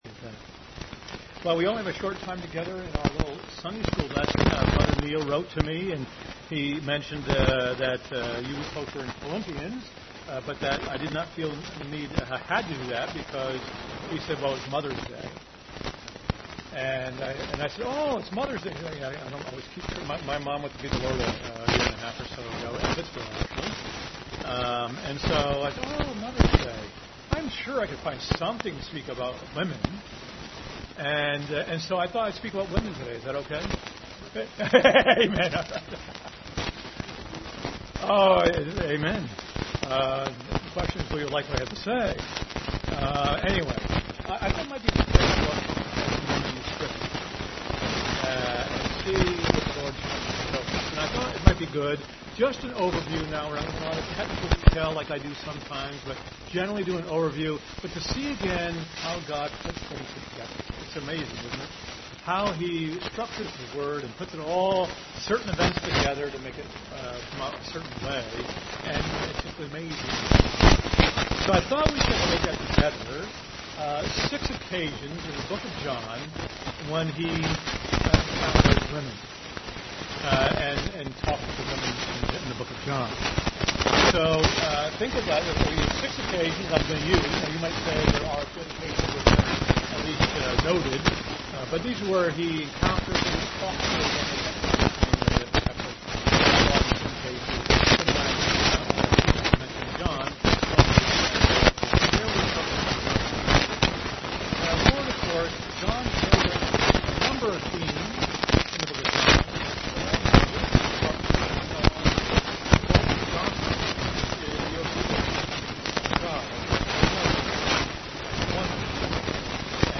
Passage: John 2:1-5, 4:4-26, 8:2-12, 11:2-27, 19:25-27, 20:11-13 Service Type: Sunday School